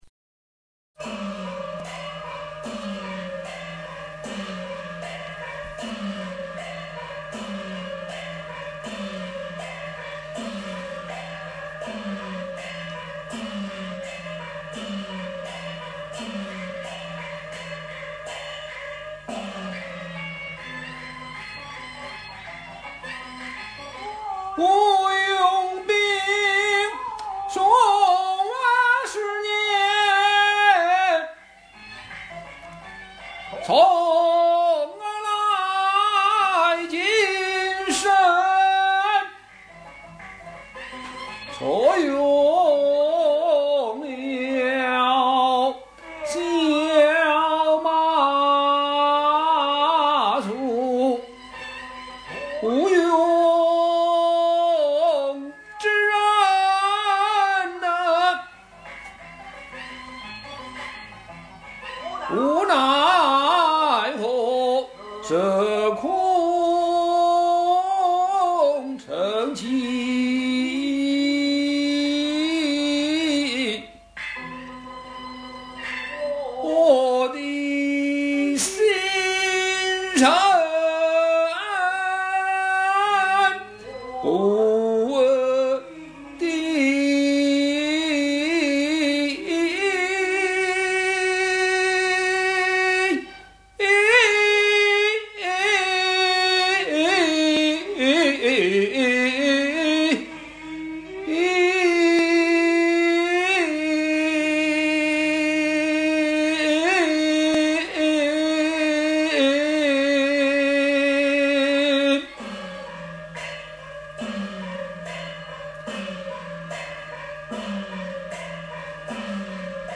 京剧
摇板：我用兵数十年从来谨慎
这是有两段很精彩的摇板，表现孔明的那种无奈、心虚的囧景。
嗯，唱出了孔明的无奈！
有时听京剧要听古韵味，这一段就有。